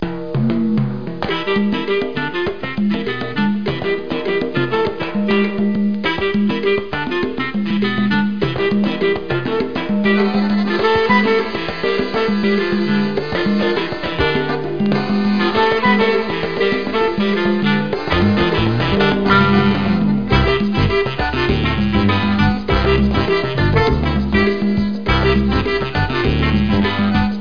salsa.mp3